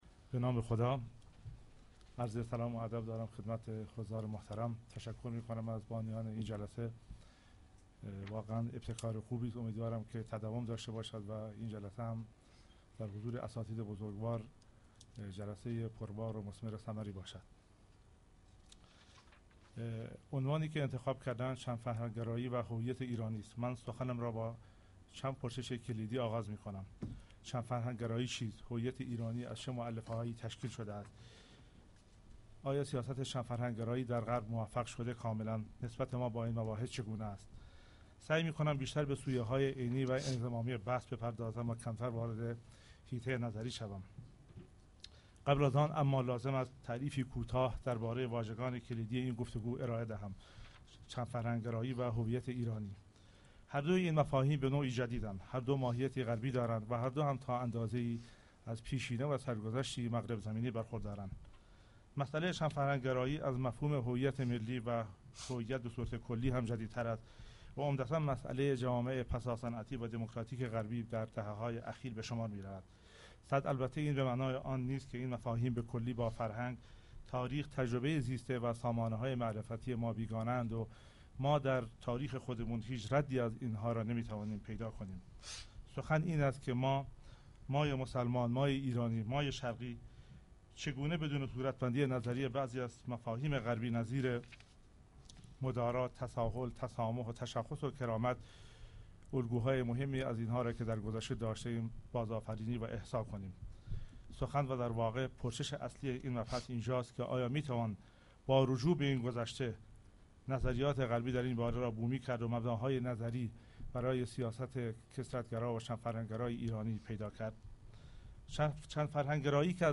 پژوهشکده مطالعات اجتماعی برگزار می کند: